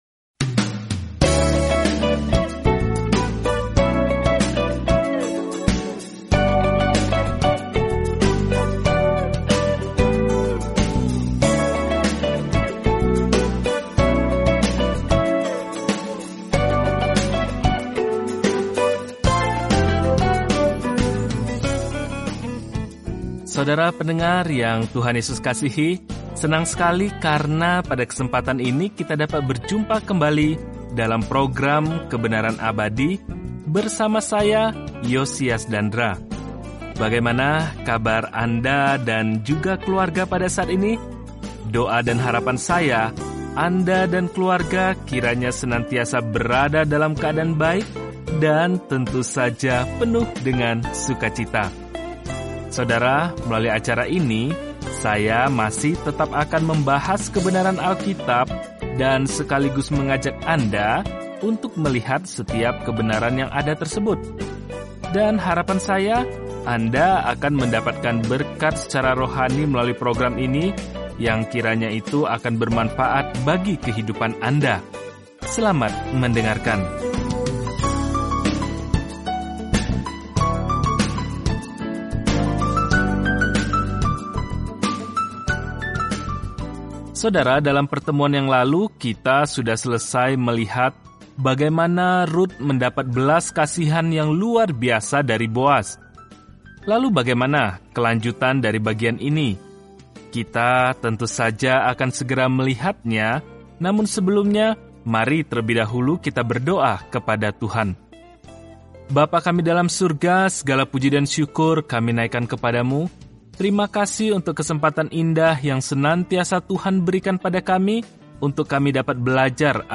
Firman Tuhan, Alkitab Rut 3:1-3 Hari 4 Mulai Rencana ini Hari 6 Tentang Rencana ini Ruth, sebuah kisah cinta yang mencerminkan kasih Tuhan kepada kita, menggambarkan pandangan sejarah yang panjang – termasuk kisah raja Daud… dan bahkan latar belakang Yesus. Jelajahi Rut setiap hari sambil mendengarkan pelajaran audio dan membaca ayat-ayat tertentu dari firman Tuhan.